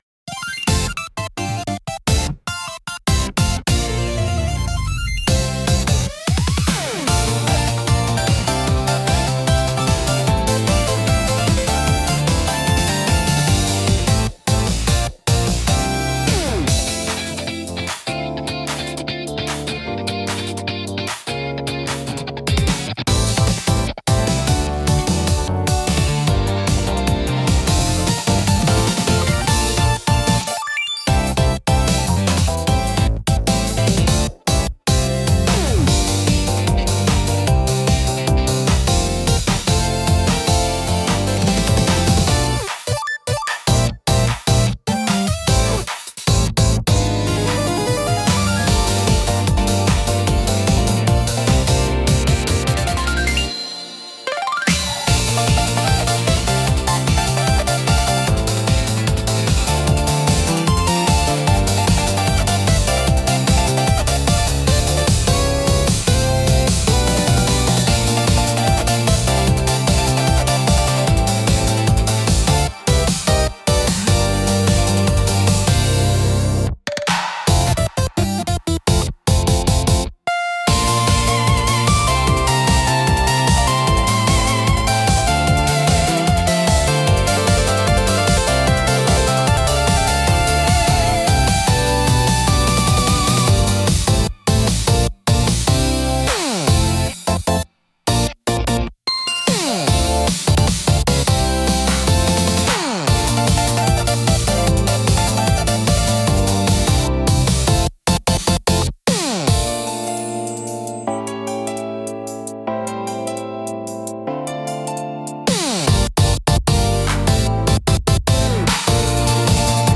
明るく軽快なシンセサウンドとリズミカルなビートが疾走感を生み出し、スピード感あふれるエネルギッシュな空気を演出します。
BGMセミオーダーシステム レースは、アップテンポで爽やかなシンセポップが主体の楽曲です。